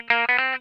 TWANGY 3R.wav